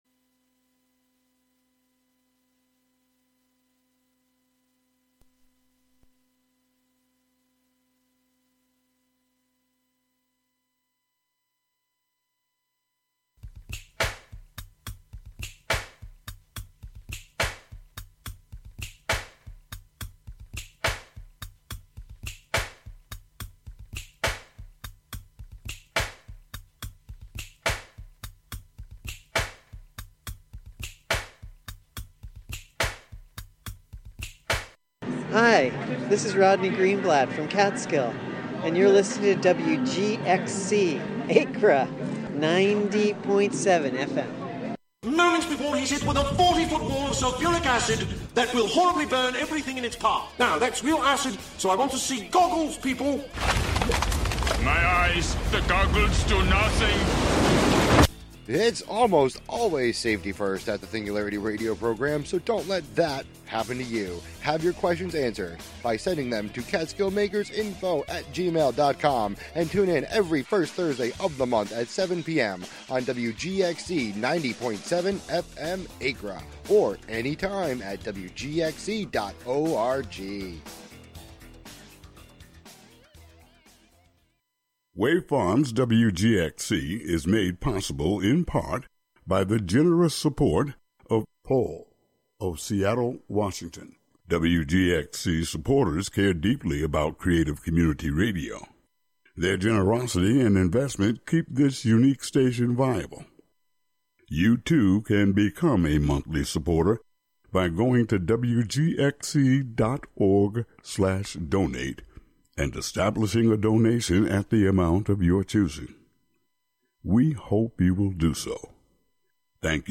Hosted by youth at Greater Hudson Promise Neighbor...
Hosted by Greater Hudson Promise Neighborhood youths, "The Promise Cool Kidz" is a half hour of talk, rap battles, songs, and more broadcasting live from GHPN!